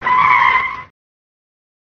Descarga de Sonidos mp3 Gratis: derrape 1.
tires-squealing.mp3